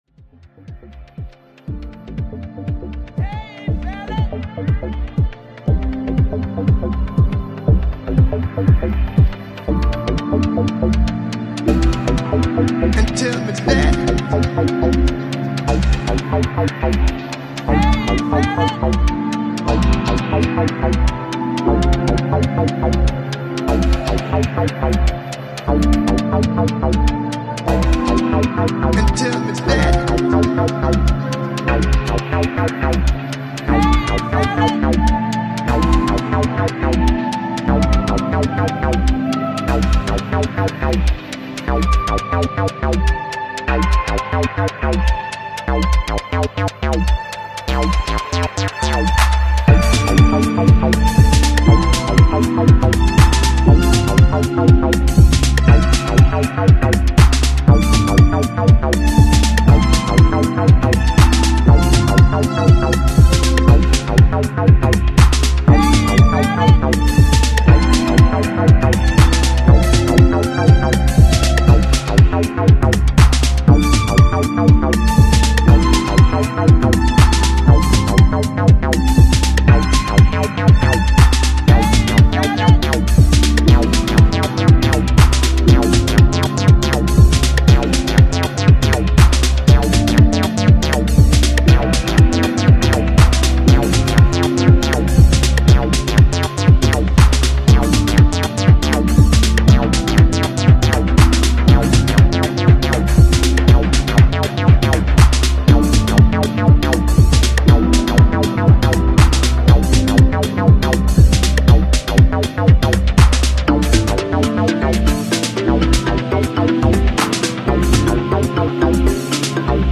Genre:House, Deep House